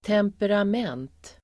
Uttal: [temperam'en:t]